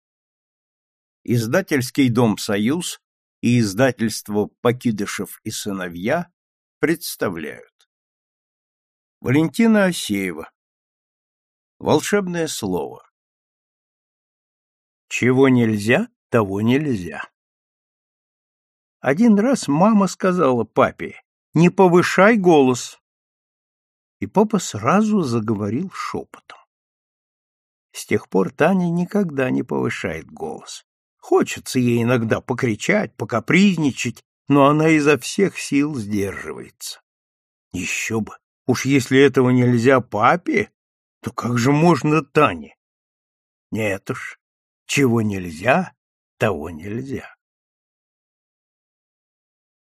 Аудиокнига Рыжий кот. Рассказы для детей | Библиотека аудиокниг